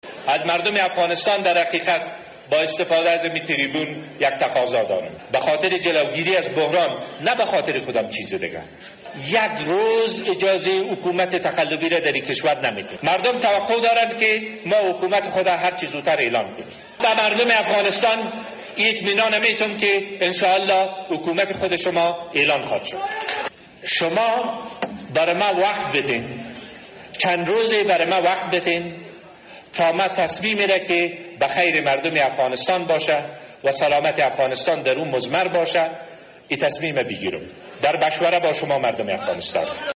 سخنرانی عبدالله عبدالله، ۱۷ تیر ۹۳، کابل